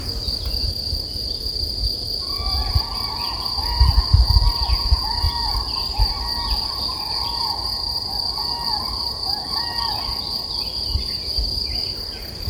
Kranich
August 2023 In Bezug auf Tonaufnahmen Kategorien: Tonaufnahmen Schlagwörter: Kranich , Natur , Tiergeräusche , Tonaufnahme , Umwelt Schreibe einen Kommentar
kranich.mp3